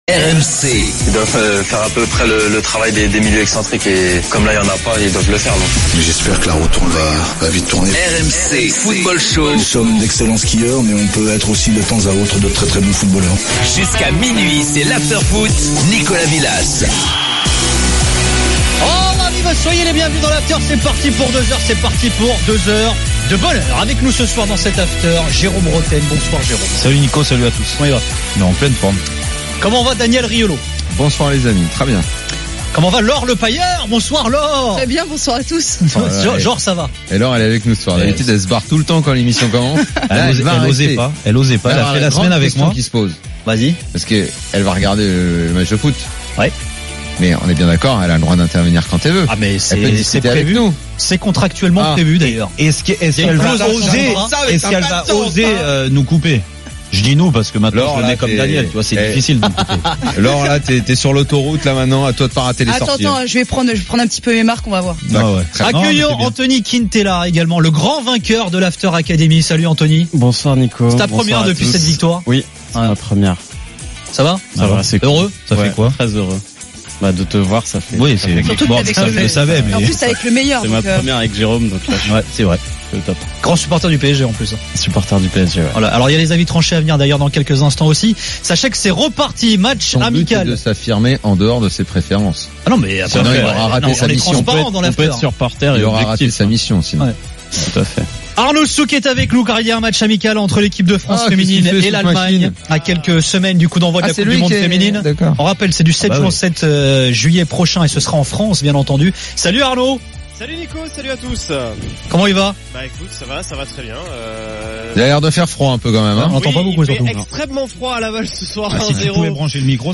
Après le match, avec Gilbert Brisbois, Daniel Riolo et Jérôme Rothen, le micro de RMC est à vous !